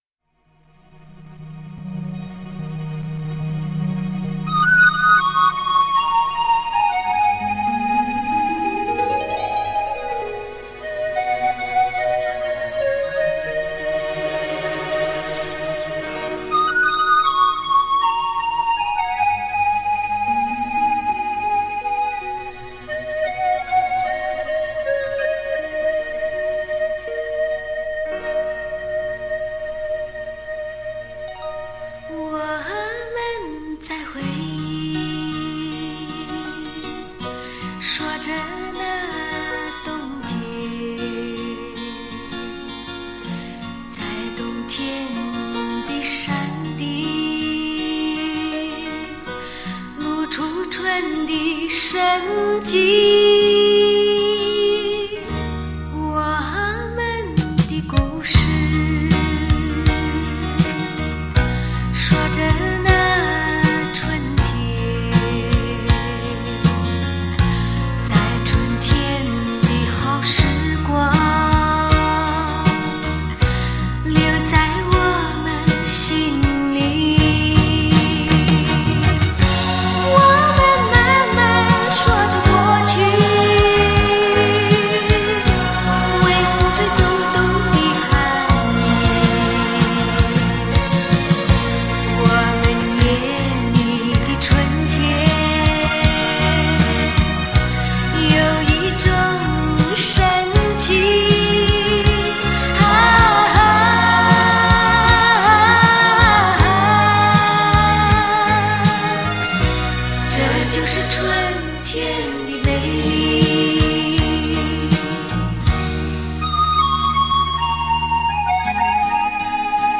德国名厂黑胶专利技术 日本JVC作特别低音处理
完善高端LP系统听觉 动态惊人 音色极佳
温馨提示：日本JVC特别低音处理，试听时请注意调节BASS（低音）